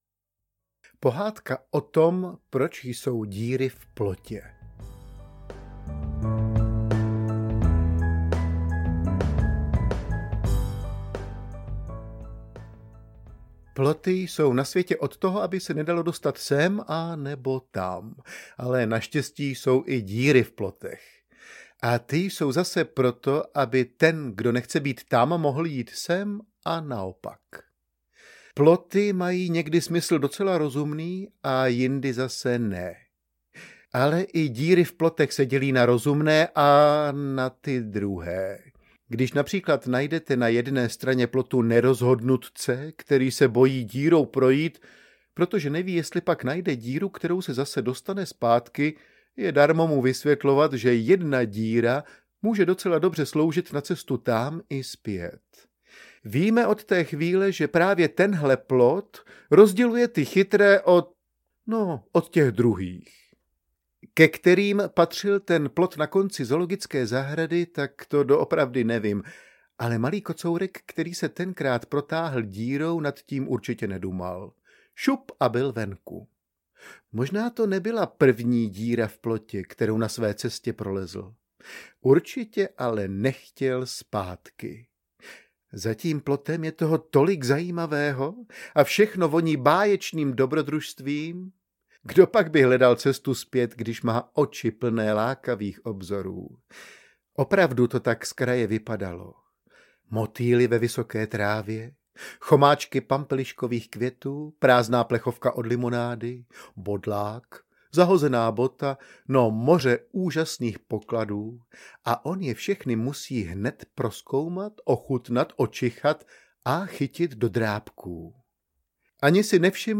Pohádky na pátky audiokniha
Ukázka z knihy